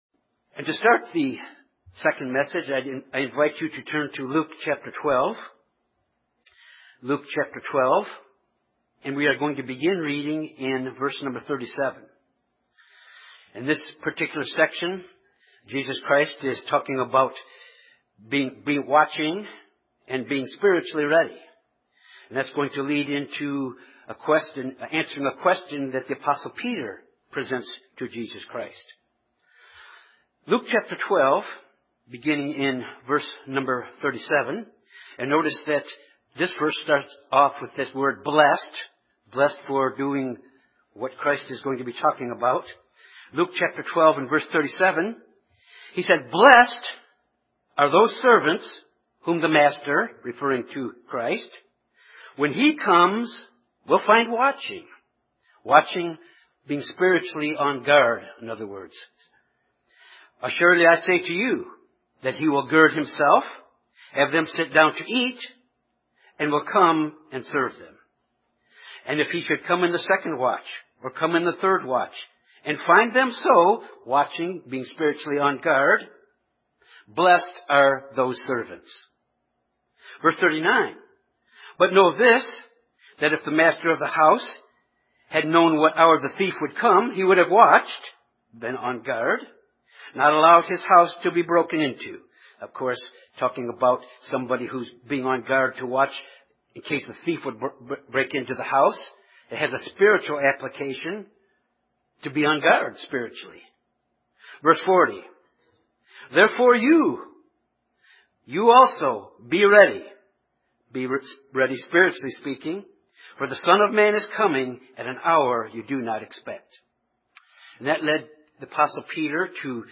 As we return from the Feast, we must go forward to complete the task we’ve been called to do. This sermon emphasizes the importance of going forward in spiritual growth this year and staying the course and keep our hand to the plow.